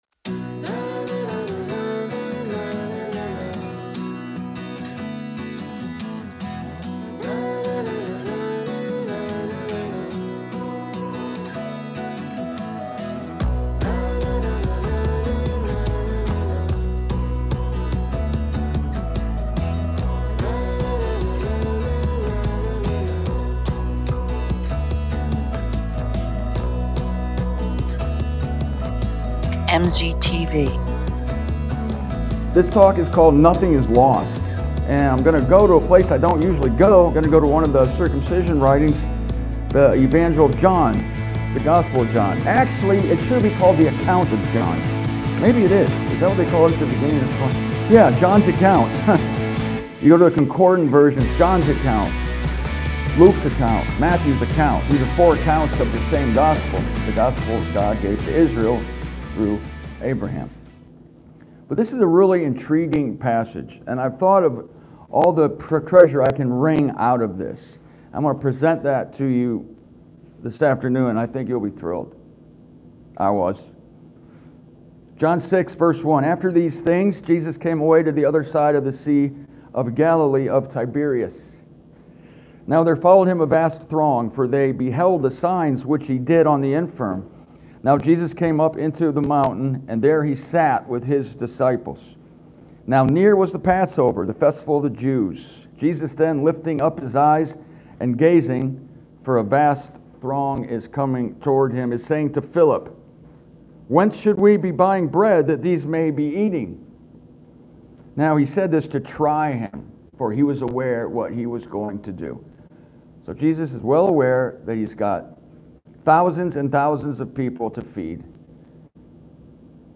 Here is my third talk from the Nebraska conference from September of last year.